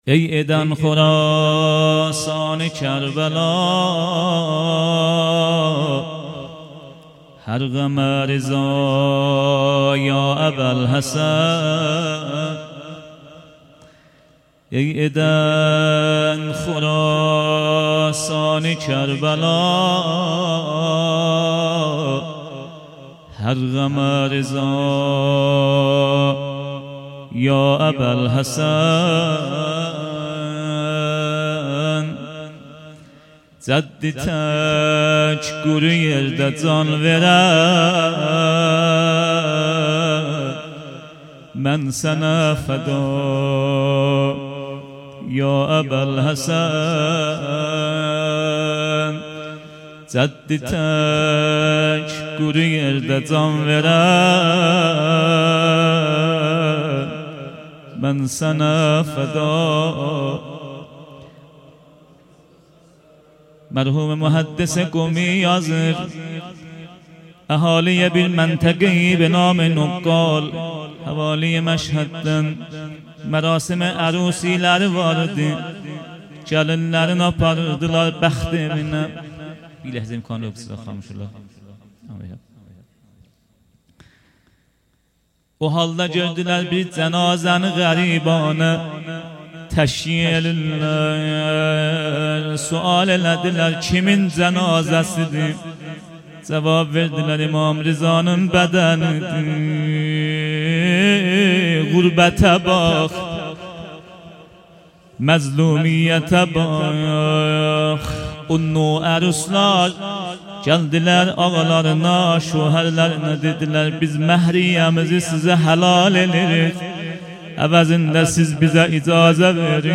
هیات هفتگی
روضه پایانی